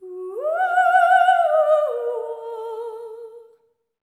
LEGATO 05 -L.wav